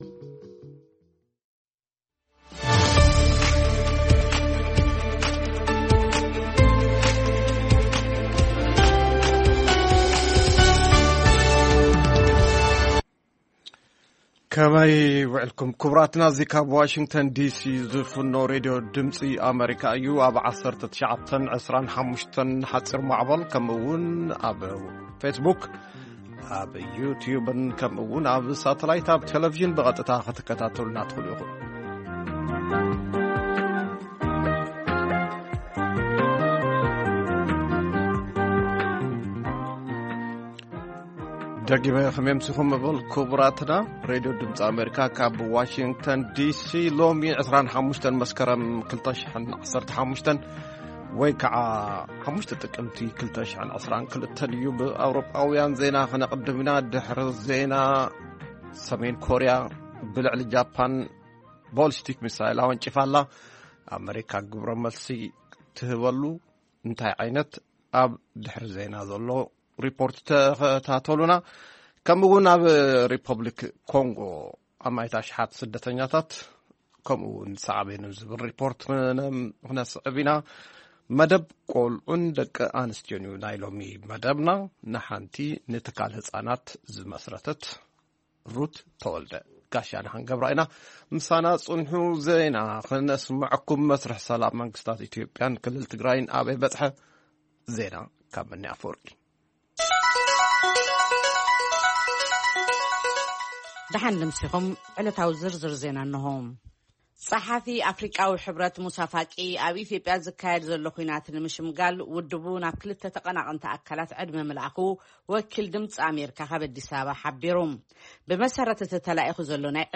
ዜና ( ሕብረት ኣፍሪቃ ቀጥታዊ ዘተ ኣብ መንጎ መንግስቲ ኢትዮጵያን መራሕቲ ክልል ትግራይን ክካየድ ጸዊዑ
ቃለ መጠይቕ